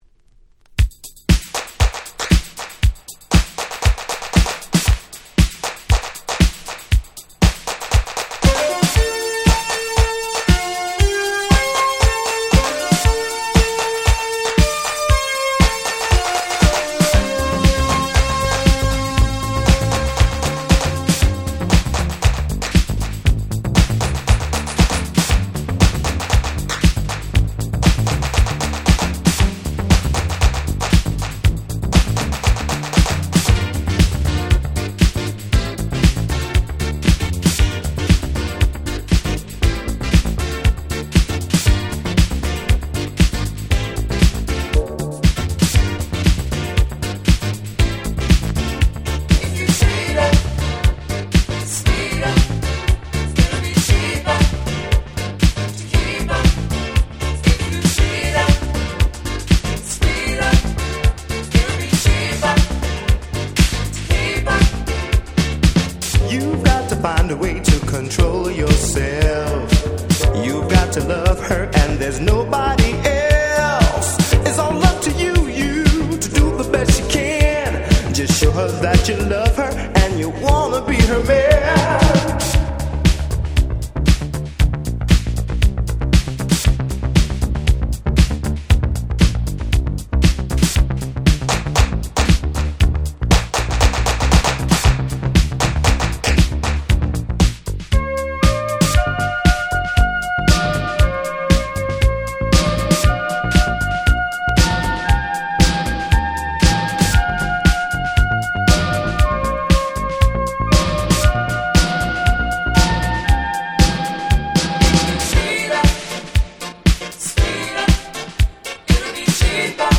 85' Nice Disco Boogie !!
詳細不明の使えるElectric Disco !!
キャッチーでPopなディスコトラックに爽やかな女性Vocalが◎！！
ダンクラ ダンスクラシックス Dance Classics ブギー 80's